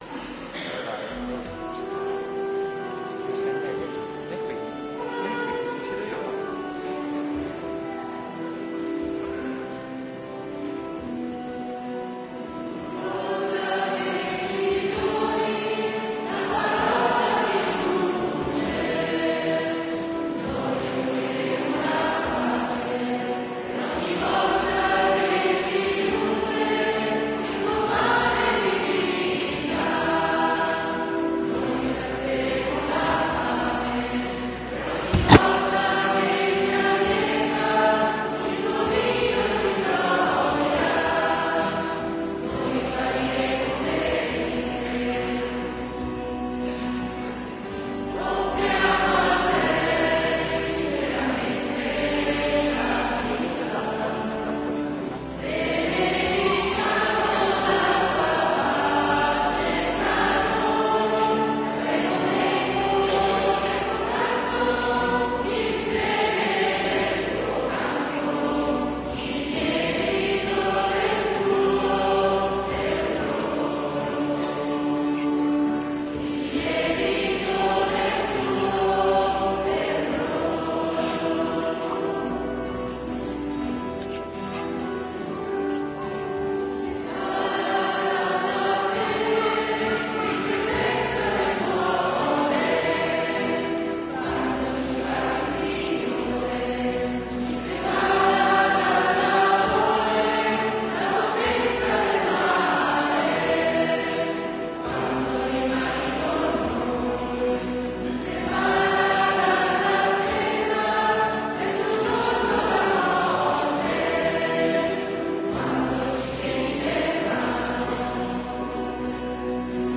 Raduno Giovani 2011 S.Messa – audio